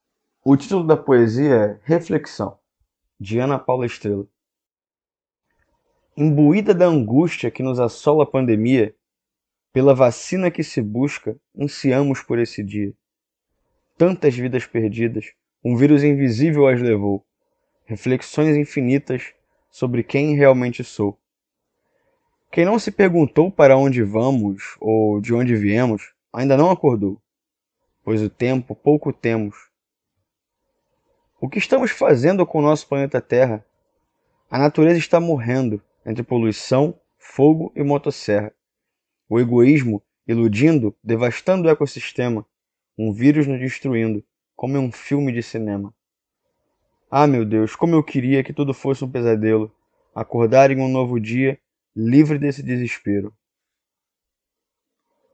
Poesia com voz humana